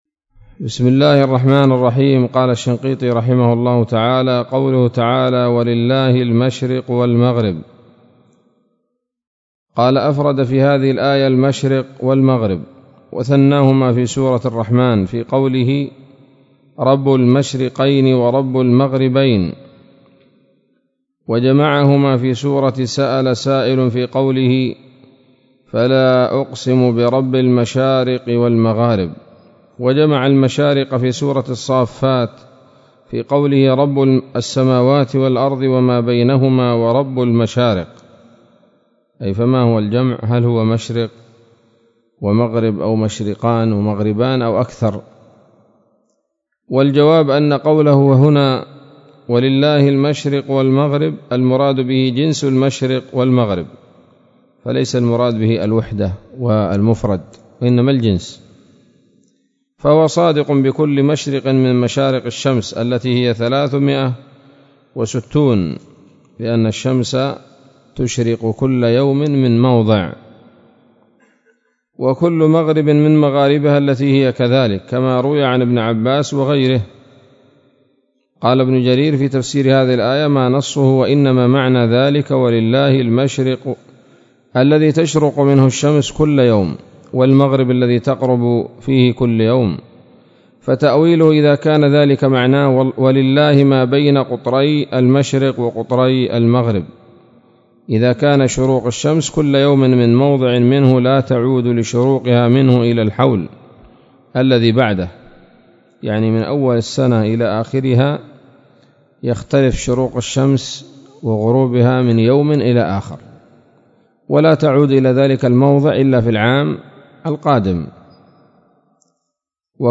الدرس الثالث عشر من دفع إيهام الاضطراب عن آيات الكتاب